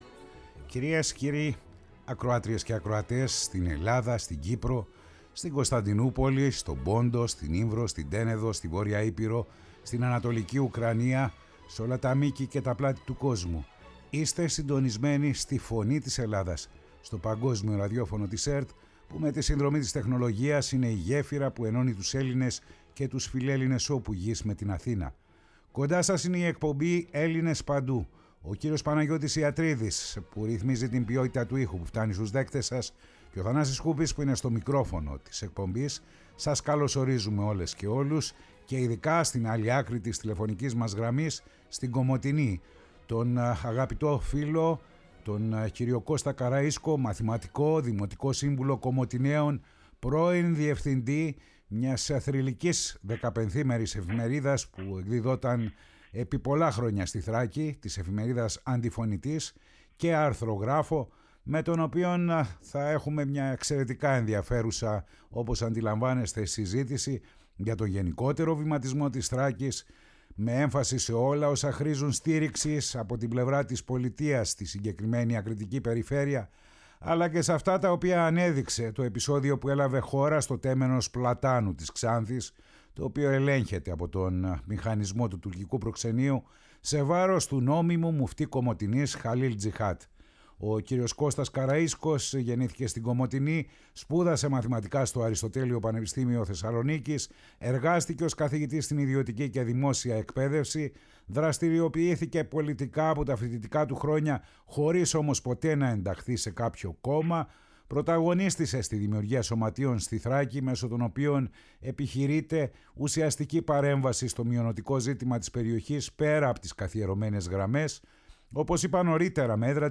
Ο Κώστας Καραΐσκος, Μαθηματικός, Δημοτικός Σύμβουλος Κομοτηναίων, Αρθρογράφος, στους “Έλληνες παντού”.
Η ΦΩΝΗ ΤΗΣ ΕΛΛΑΔΑΣ Ελληνες Παντου ΣΥΝΕΝΤΕΥΞΕΙΣ Συνεντεύξεις Κωστας Καραισκος